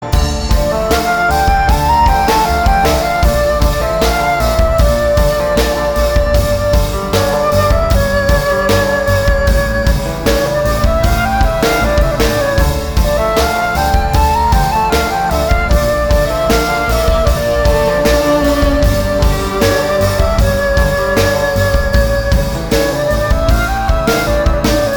• Качество: 211, Stereo
спокойные
без слов
скрипка
инструментальные
фолк
романтические